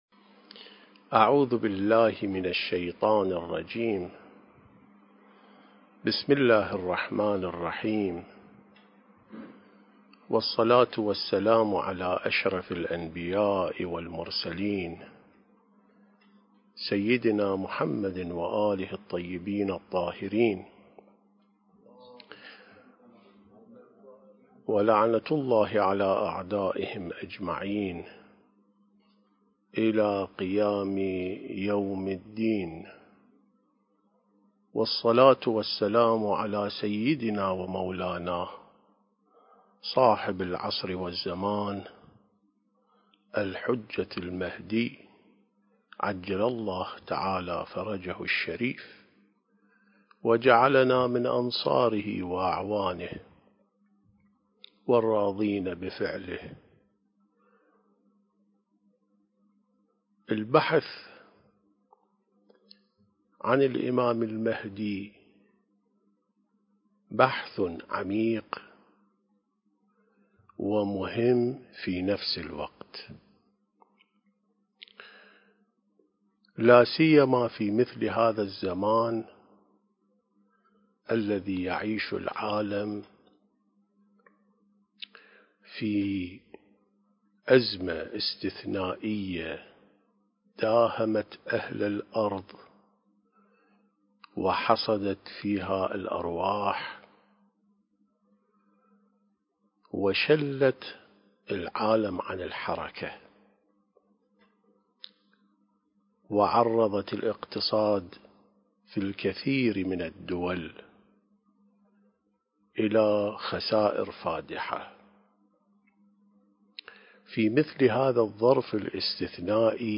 الحجم: ٧.١٩ MB سلسلة محاضرات كيف تكون مهدوياً؟